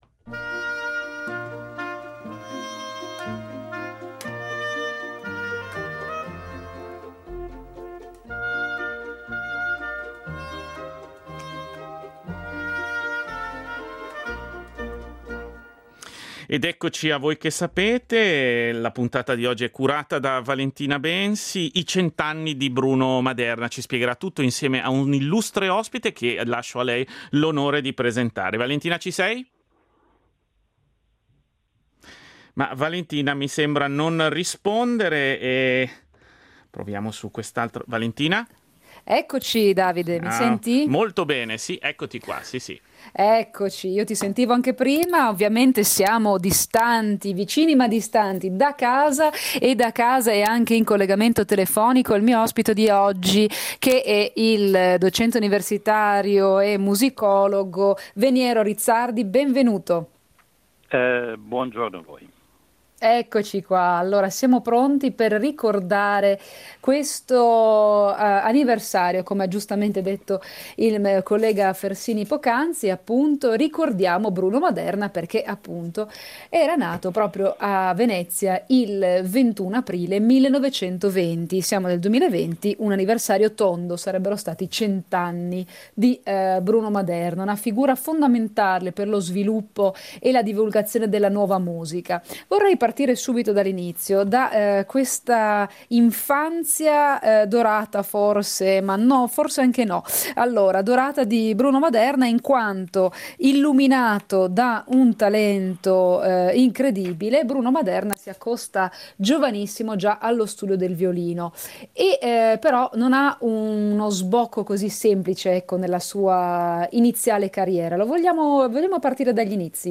musicologo